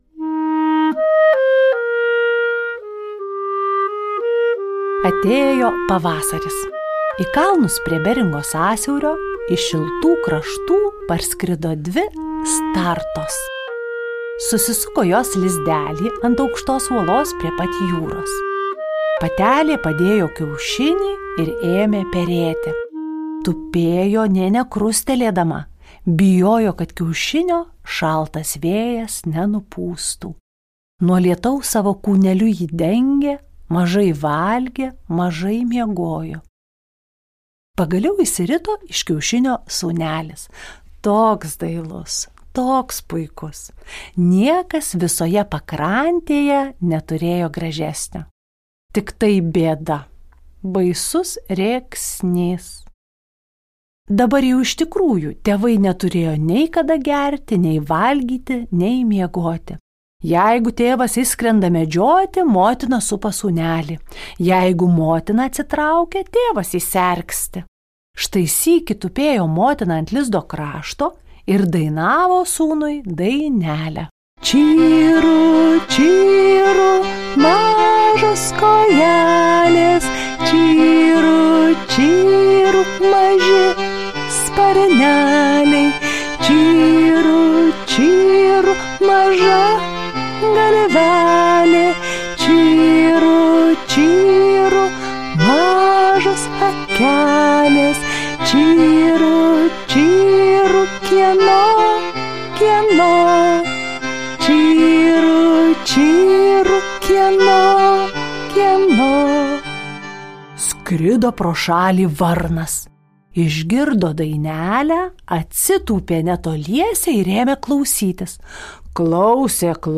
Tinklalaidė įrašyta Lietuvos nacionalinės Martyno Mažvydo bibliotekos garso įrašų studijoje